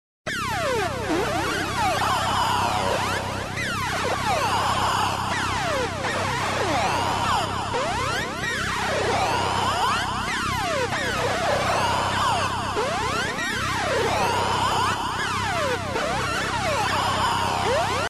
Звуки ТАРДИС